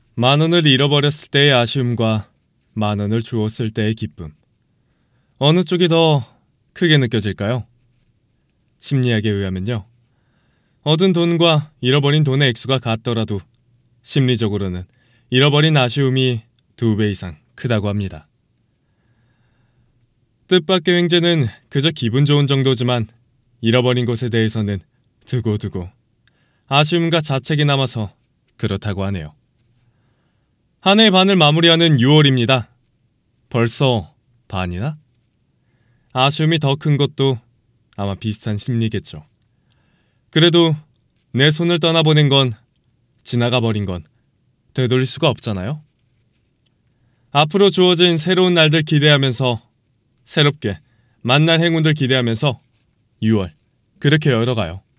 [남자성우] 남성의 쿨한 목소리 입니다! (나레이션, 성우, 캐릭터)
성우 공부나 연극과 같은 연기를 공부한 지 4년 차가 넘어서 여러 상황의 목소리 연기가 가능합니다!
-녹음은 방음 부스에서 진행이 되며 외적인 소리로 인해 문제가 생길 시 다시 재녹음을 해드립니다!